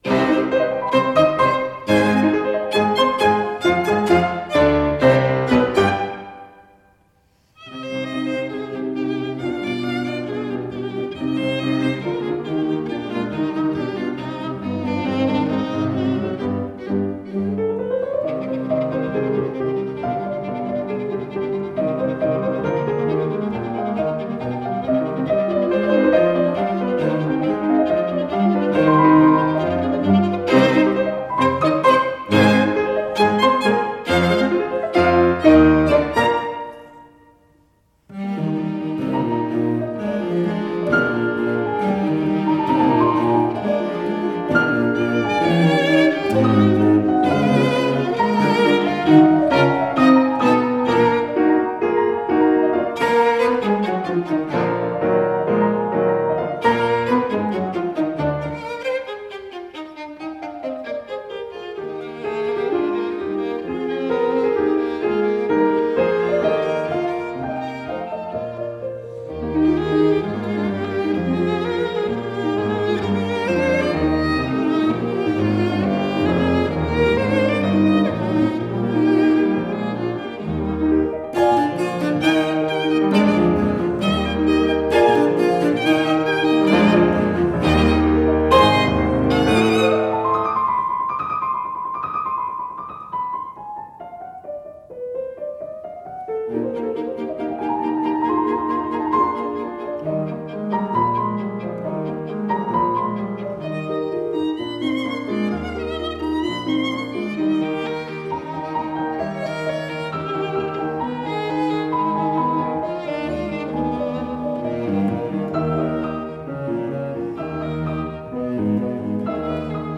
大 提 琴
钢 琴